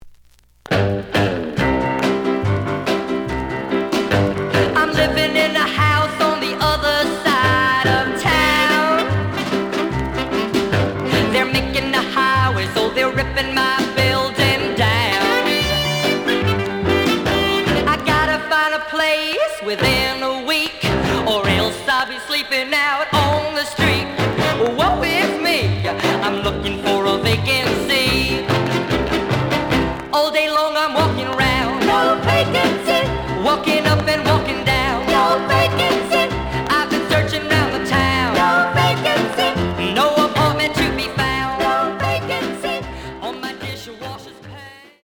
The audio sample is recorded from the actual item.
●Genre: Rhythm And Blues / Rock 'n' Roll
Some click noise on first half of B side.